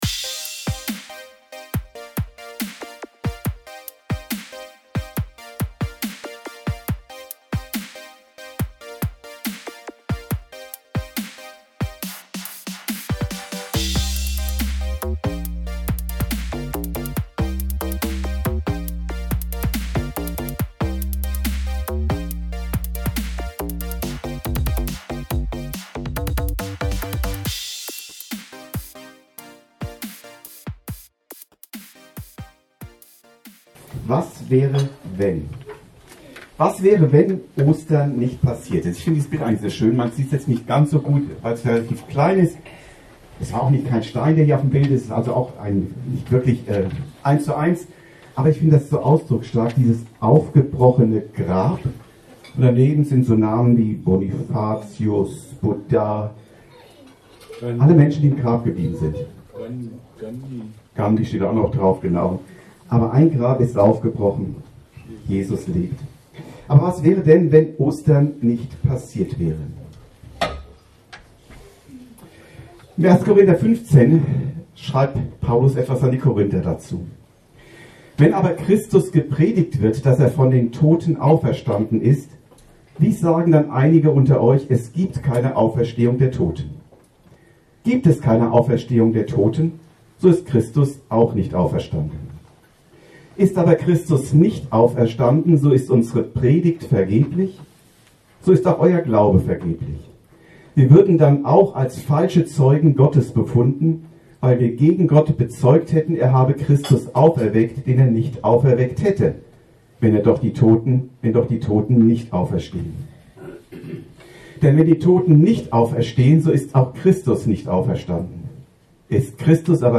Ostern - was wäre wenn... ~ Predigten u. Andachten (Live und Studioaufnahmen ERF) Podcast
Andacht über 1.
im Rahmen des Osterfrühstücks in der Evangelischen Freien Gemeinde Nordhorn